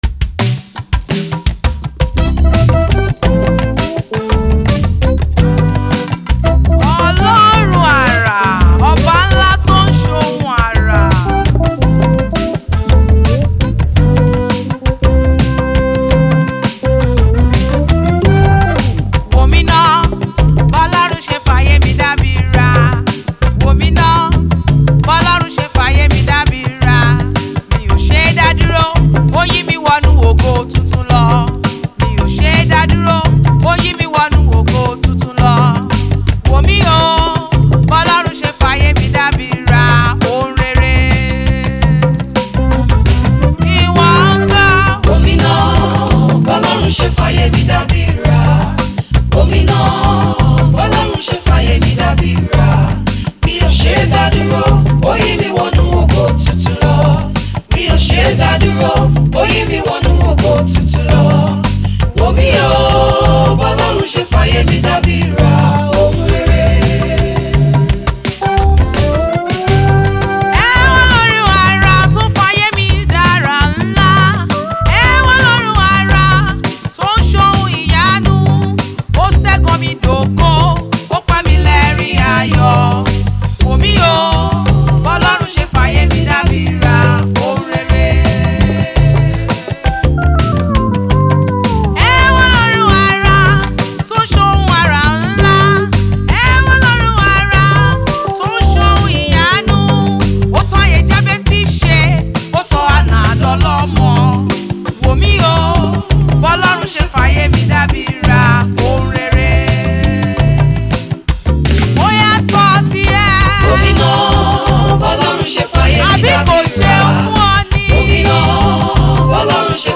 Nigerian gospel music
soul-stirring gospel track